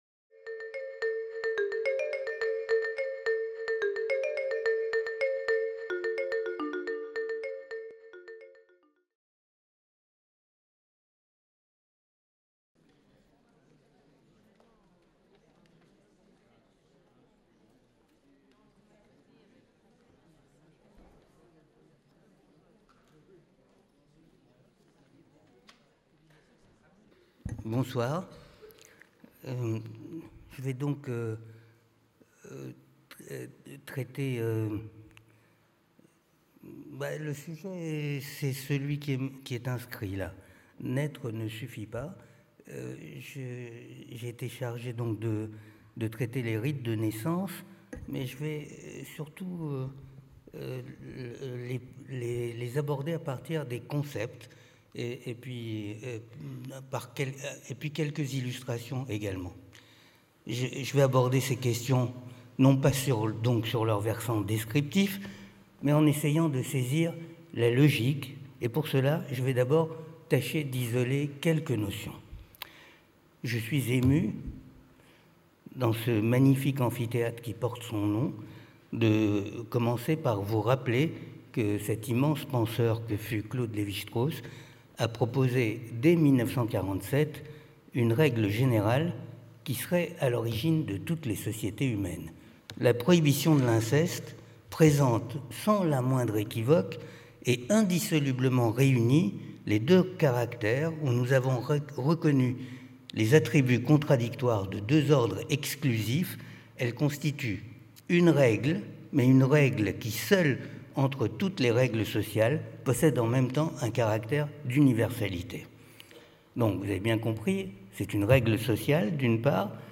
Conférence de l’Université populaire du quai Branly (UPQB), donnée le 6 janvier 2016 Commencé en 2015, ce cycle continue d’interroger les différentes facettes de l’enfance et ses étapes, à travers les pays et les cultures, la psychologie, la philosophie, la bande dessinée ou la littérature.